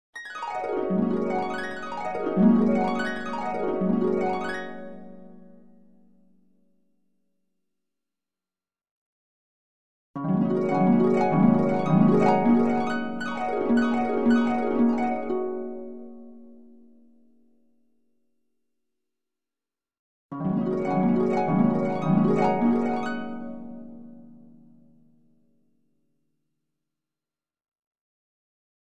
Dream Harp Sound Effect - SoundJaycom